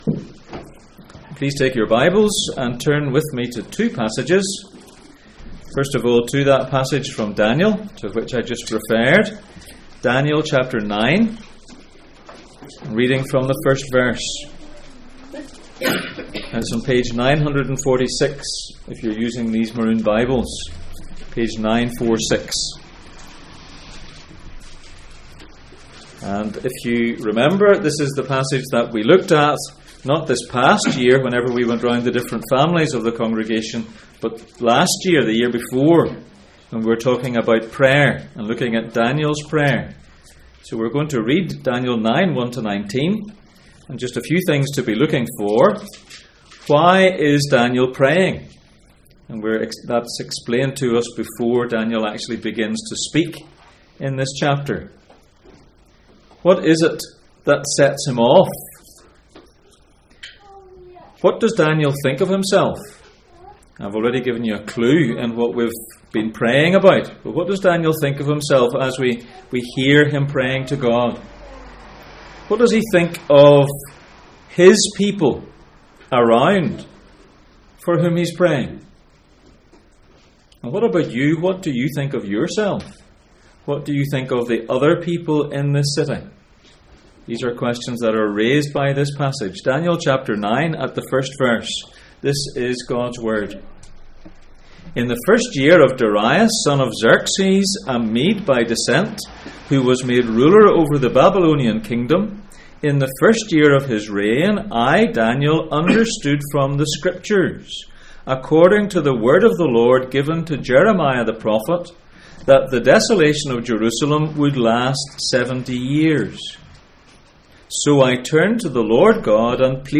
Passage: Daniel 9:1-19, Genesis 4:1-11, Deuteronomy 7:9-10 Service Type: Sunday Morning